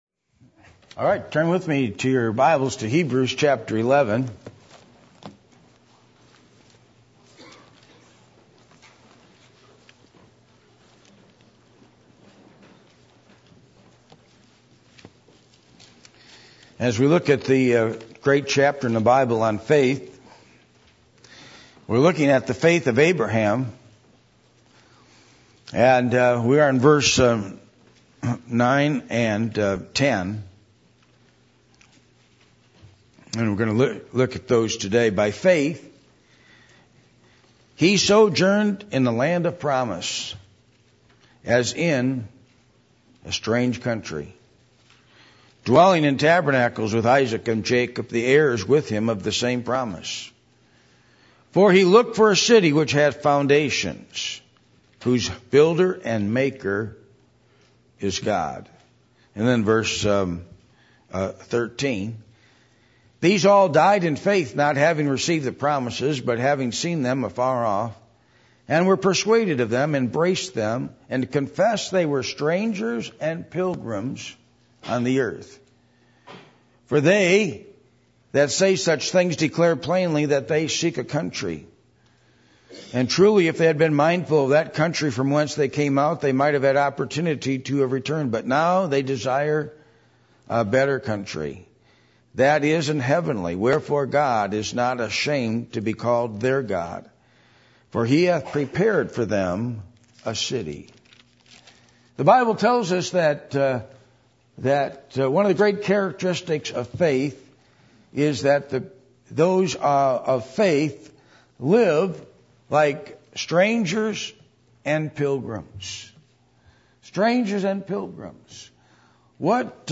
Passage: Hebrews 11:9-16 Service Type: Sunday Morning %todo_render% « What Is Your Real Opinion Of The Messiah?